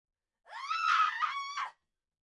Female Scream Sound Button - Free Download & Play
1. Play instantly: Click the sound button above to play the Female Scream sound immediately in your browser.